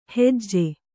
♪ hejje